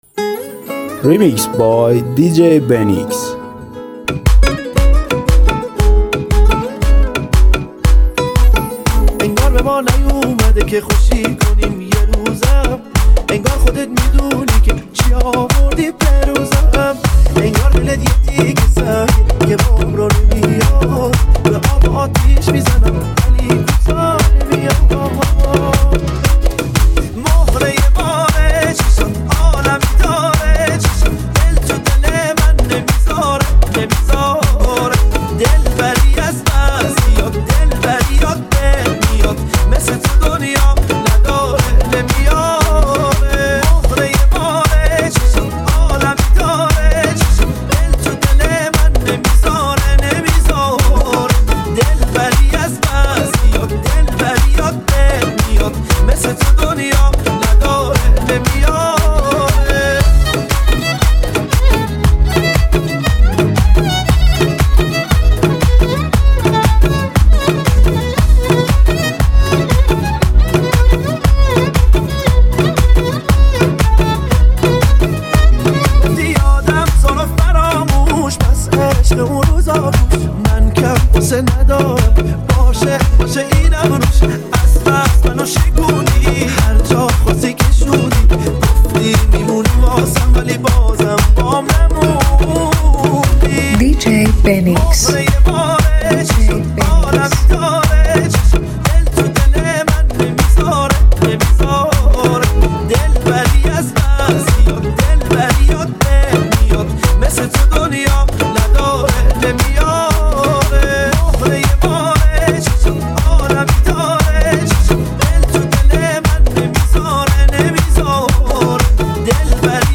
ریمیکس جدید و پرانرژی
موزیک فوق‌العاده شنیدنی و ریتمیک
یک موزیک شاد و پرانرژی